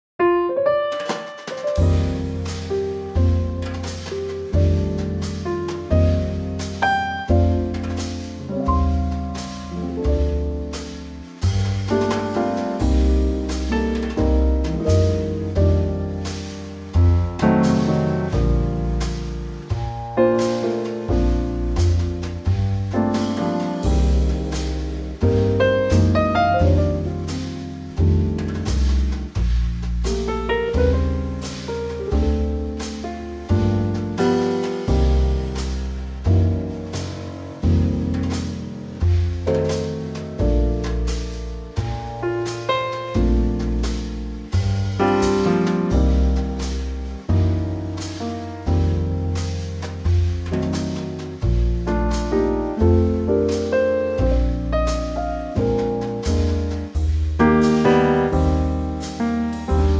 key - Bb - vocal range - F to A
Same arrangement as F2588 except a full tone lower.